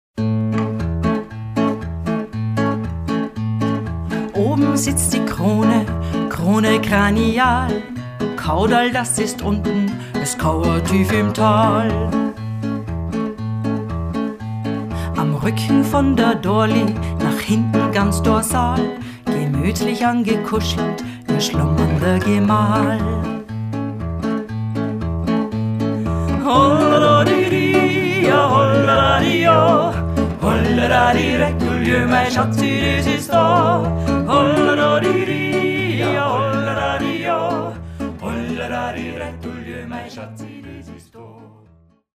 vocals, guitar, percussion, ocarina
Recorded at: Kinderzimmer Studios